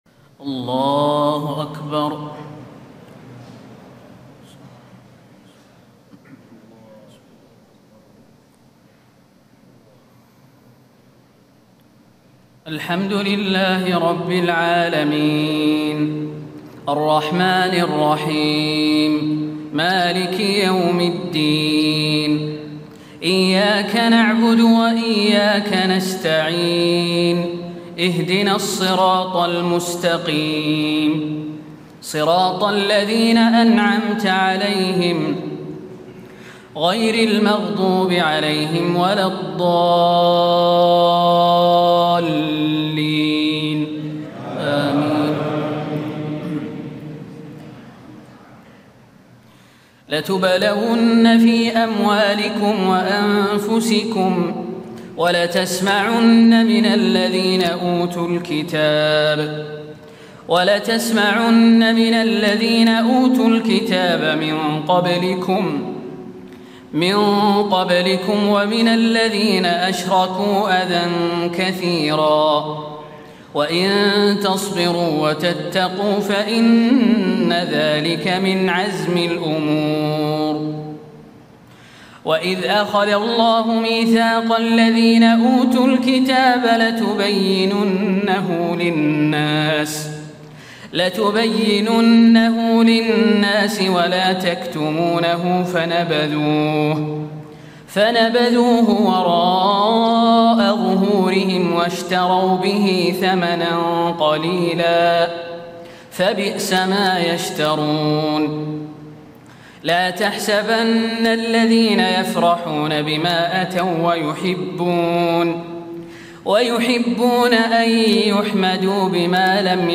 تهجد ليلة 24 رمضان 1436هـ من سورتي آل عمران (186-200) و النساء (1-24) Tahajjud 24 st night Ramadan 1436H from Surah Aal-i-Imraan and An-Nisaa > تراويح الحرم النبوي عام 1436 🕌 > التراويح - تلاوات الحرمين